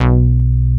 XPNDRBASS1.wav